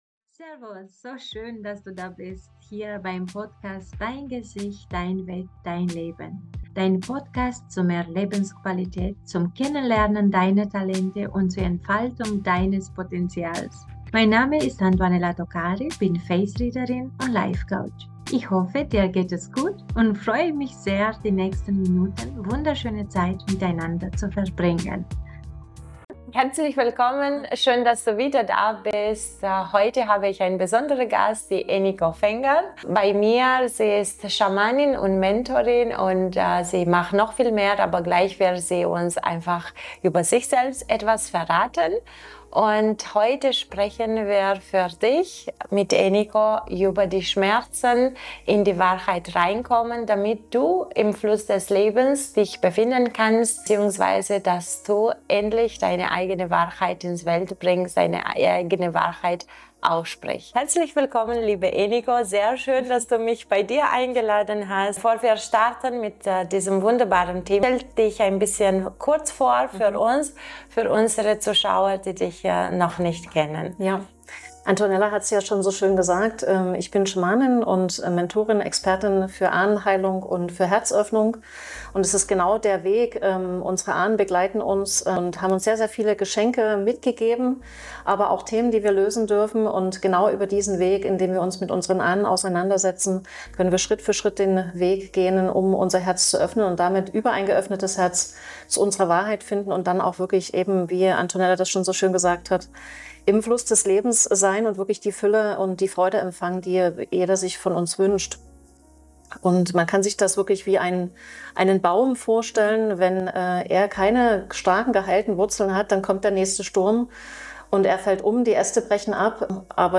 Ein ehrliches Gespräch über Authentizität, innere Heilung und den Mut, Deinen eigenen Weg zu gehen.